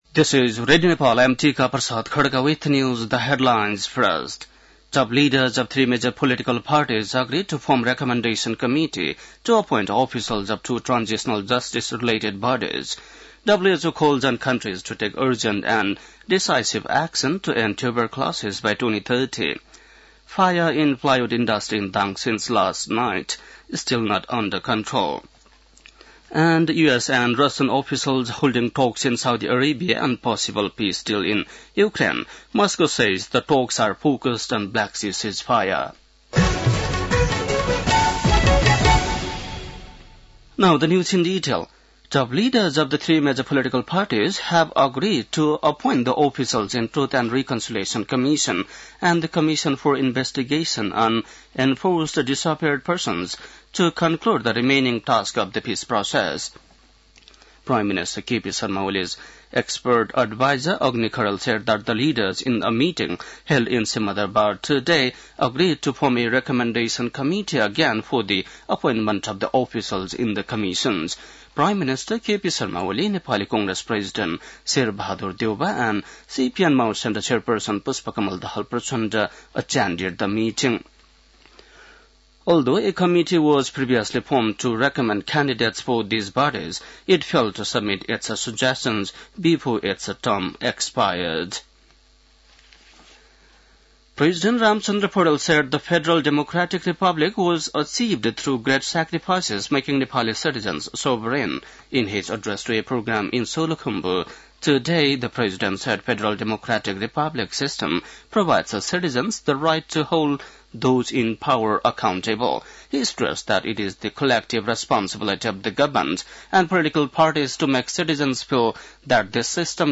बेलुकी ८ बजेको अङ्ग्रेजी समाचार : ११ चैत , २०८१
8-pm-english-news-1.mp3